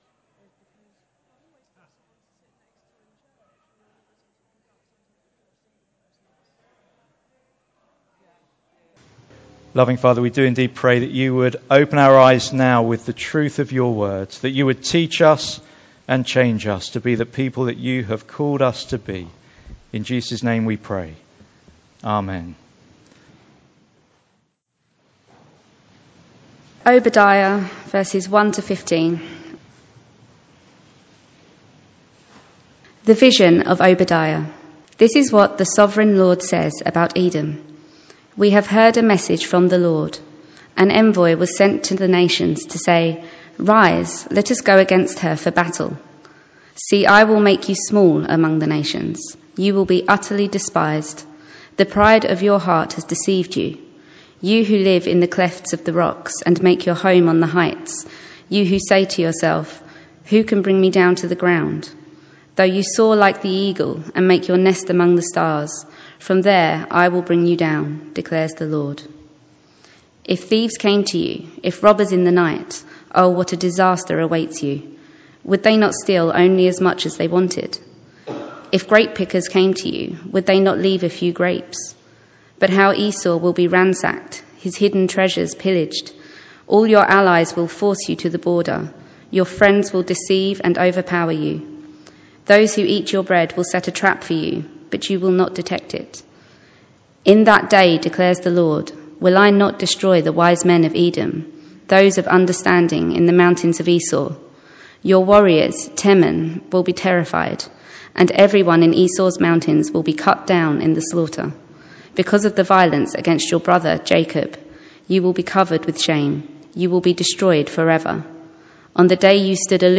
Passage: Obadiah 1:1-15 Service Type: Sunday Morning